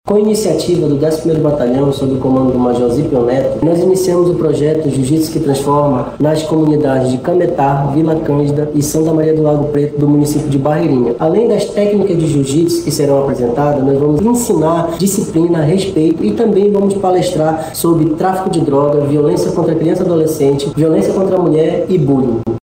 SONORA-1-PROJETO-POLICIA-BARREIRINHA-.mp3